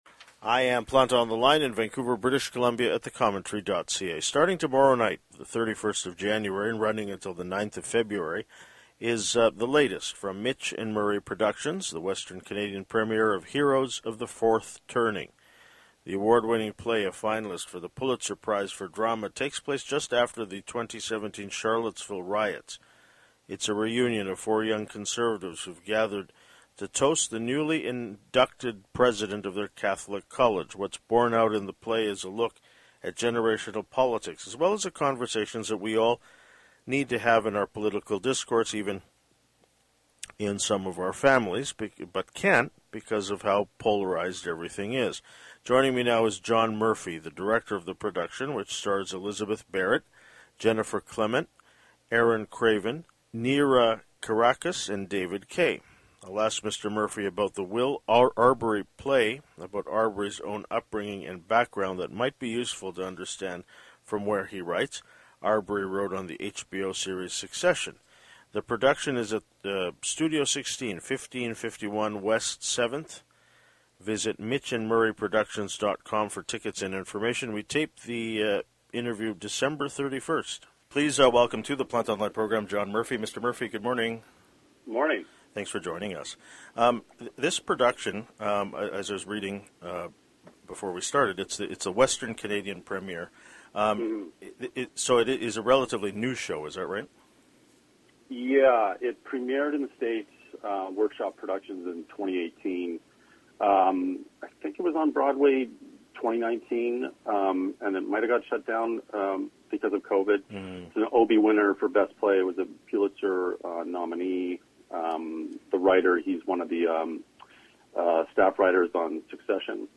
We taped this interview December 31 st (2024).